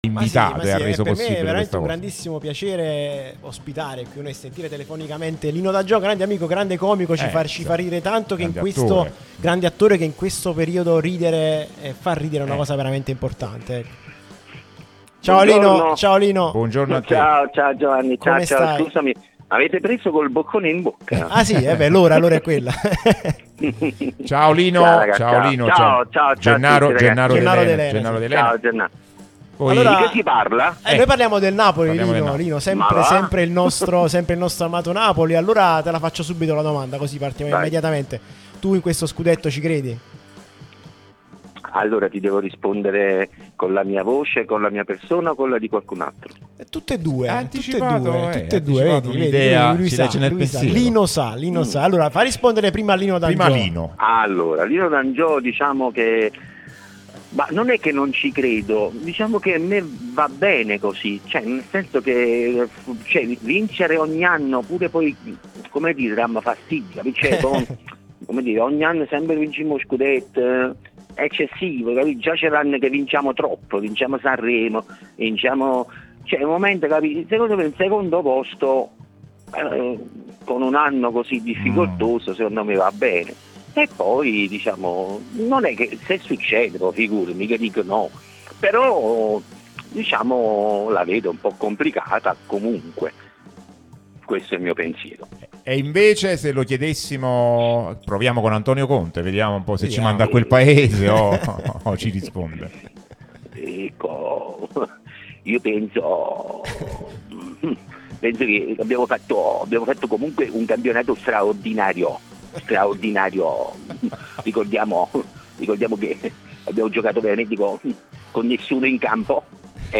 Lino D'Angiò, comico, è intervenuto sulla nostra Radio Tutto Napoli, prima radio tematica sul Napoli, in onda tutto il giorno, che puoi vedere qui sul sito o ascoltare sulle app (qui per Iphone o qui per Android) ed in auto col DAB: "Scudetto? Non è che non ci credo, però la vedo complicata.